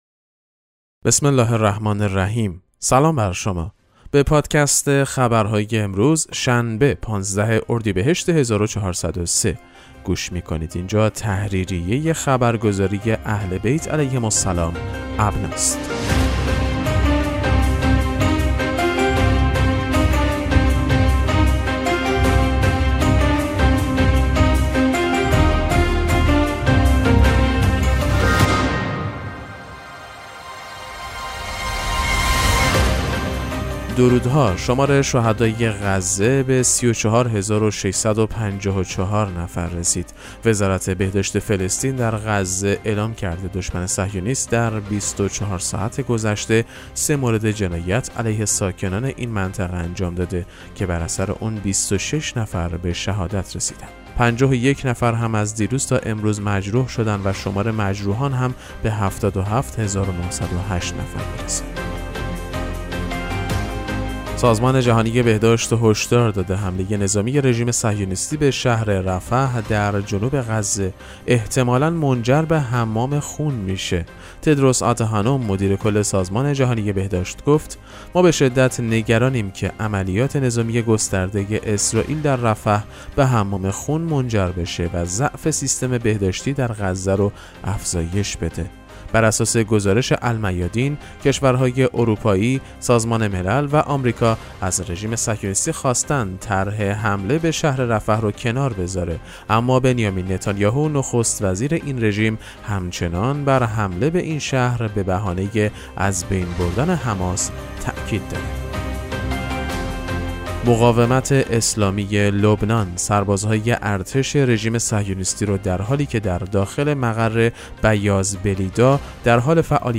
خبرگزاری اهل‌بیت(ع) ـ ابنا ـ با ارائه سرویس «پادکست مهم‌ترین اخبار» به مخاطبان خود این امکان را می‌دهد که در دقایقی کوتاه، از مهم‌ترین اخبار مرتبط با شیعیان جهان مطلع گردند. در زیر، پادکست اخبار امروز شنبه 15 اردیبهشت 1403 را به مدت 4 دقیقه و 10 ثانیه بشنوید: